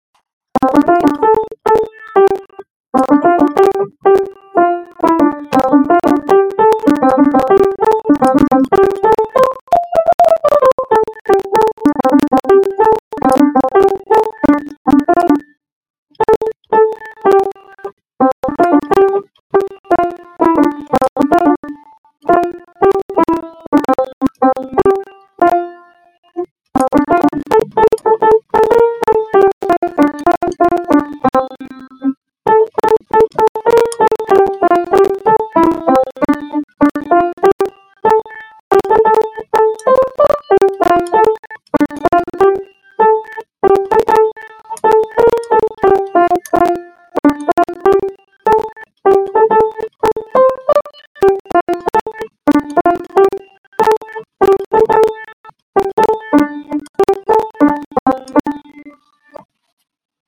手搓拼好歌
想着搞诈骗，结果被这个音质诈骗了
这波是被音质反杀了（笑哭）